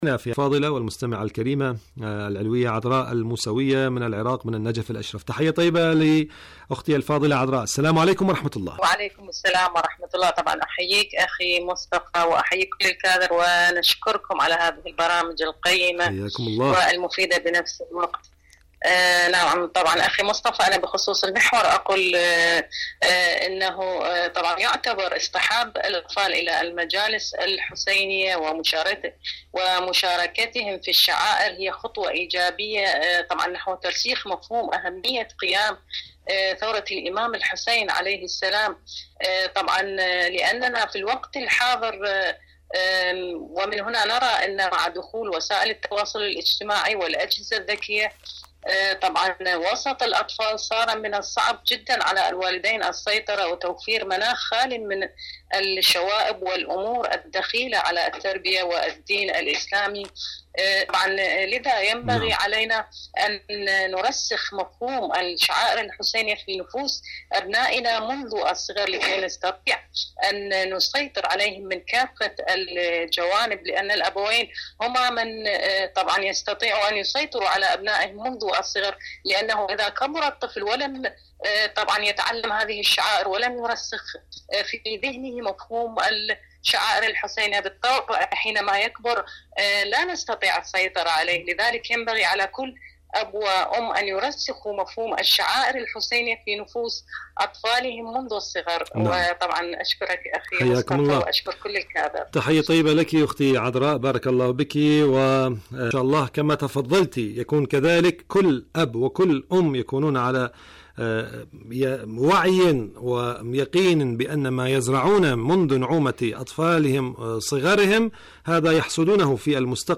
إذاعة طهران- معكم على الهواء